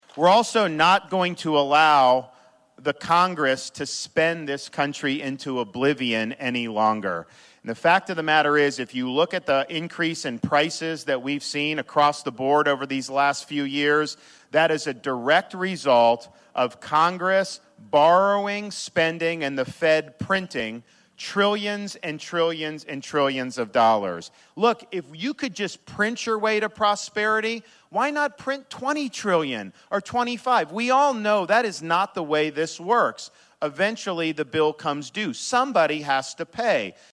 (Atlantic, IA) – Florida Governor Ron DeSantis spoke to Atlantic citizens today on multiple issues, one being a focus on the economy.